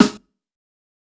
TC2 Snare 5.wav